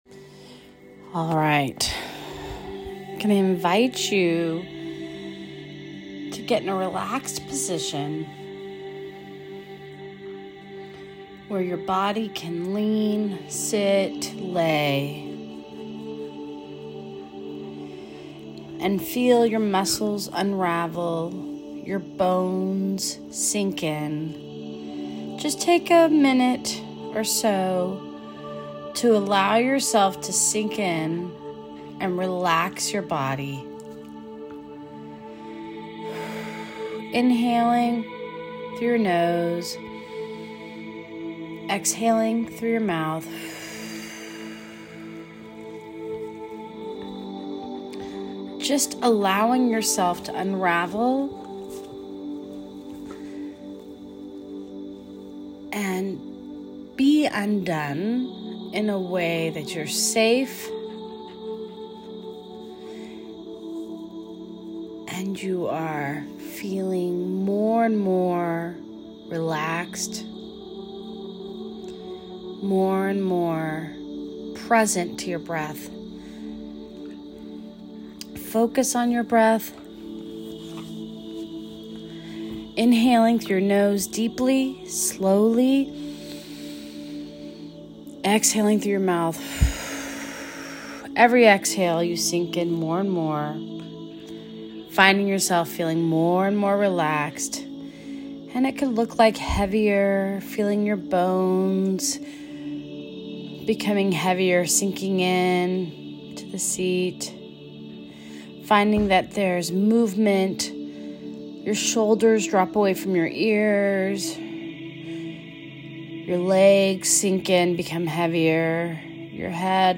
Freedom from the Critic (Audio Meditation